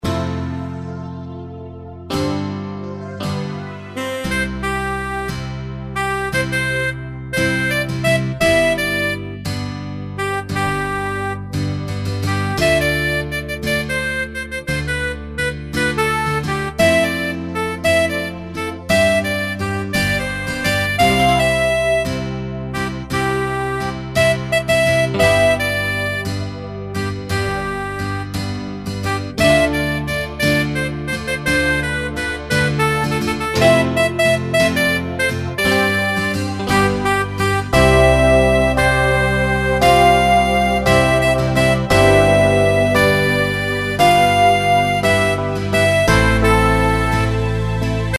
Tempo: 58 BPM.
MP3 with melody DEMO 30s (0.5 MB)zdarma